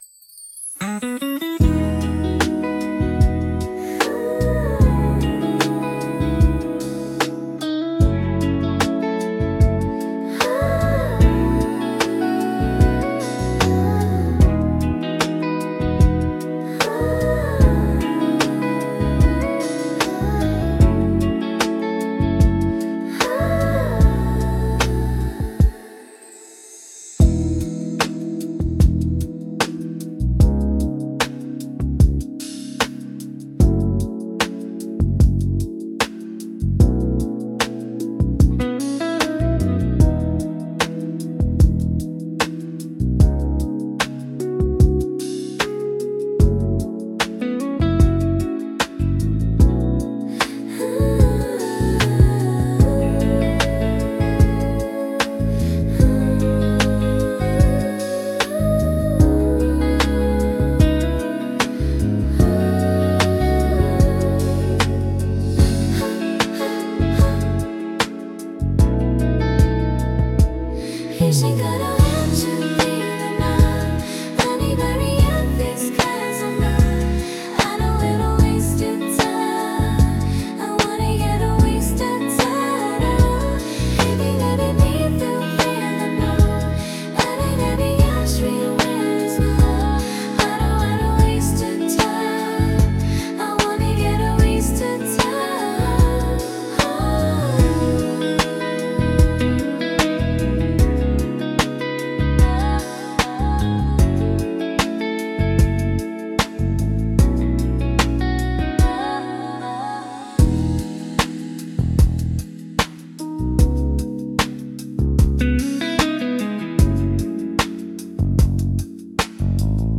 チルアウトは、ゆったりとしたテンポと滑らかで広がりのあるサウンドが特徴のジャンルです。
リラックス効果の高いメロディと穏やかなリズムにより、心地よい安らぎの空間を作り出します。
穏やかで心地よい雰囲気作りに重宝されるジャンルです。